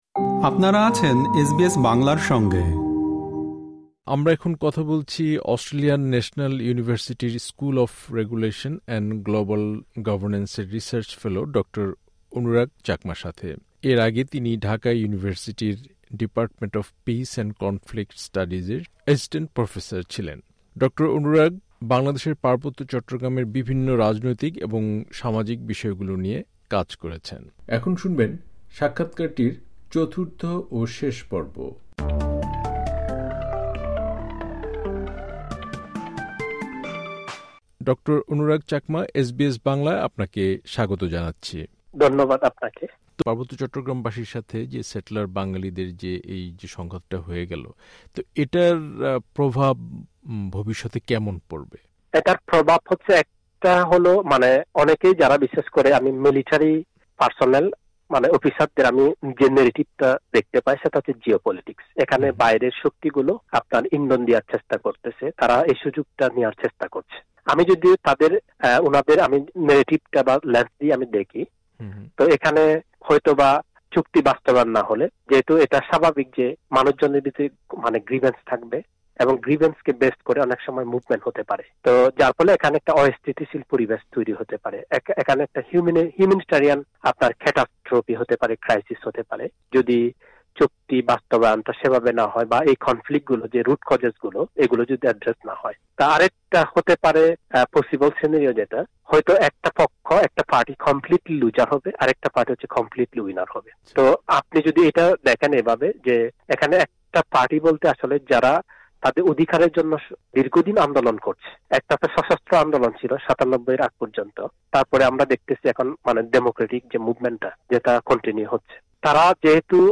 এসবিএস বাংলার সাথে এক একান্ত সাক্ষাৎকারে